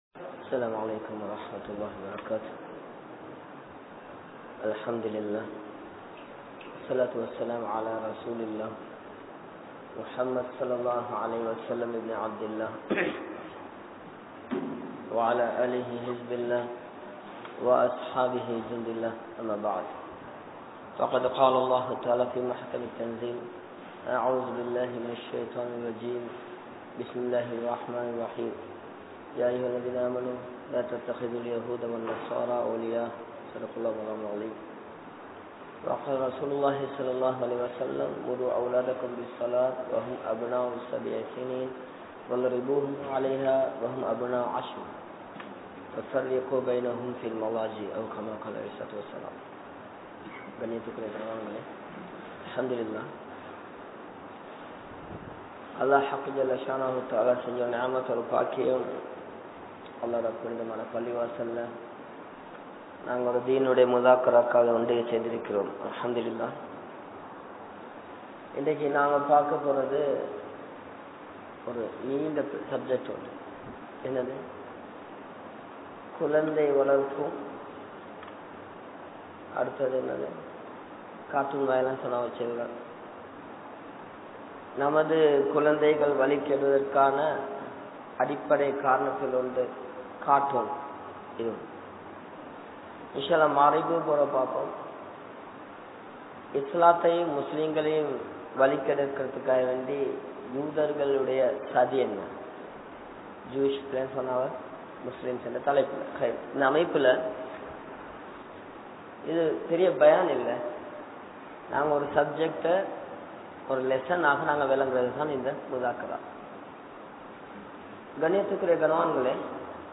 Kulanthai Valarkum Murai | Audio Bayans | All Ceylon Muslim Youth Community | Addalaichenai